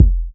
• Tight Urban Bass Drum Single Shot F Key 179.wav
Royality free kick sound tuned to the F note. Loudest frequency: 85Hz
tight-urban-bass-drum-single-shot-f-key-179-lMp.wav